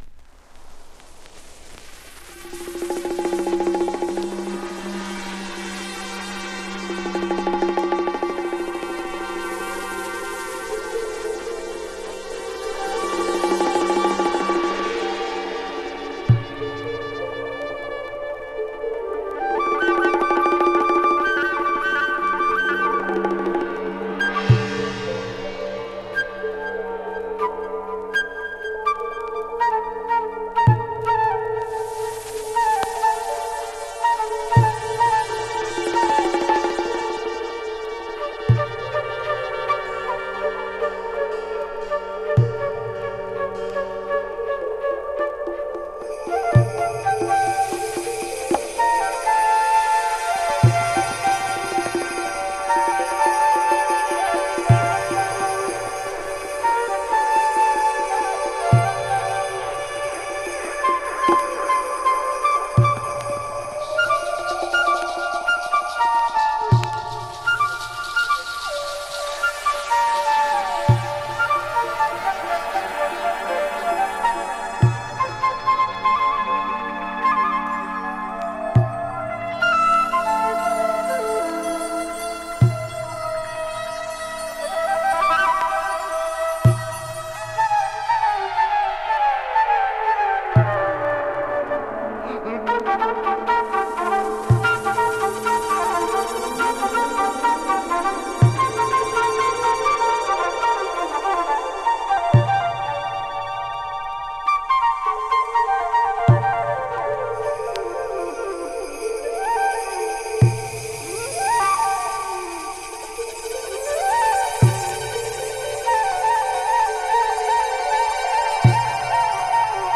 あのベースラインはそのままに、重厚なトライバル・トラックへ変換。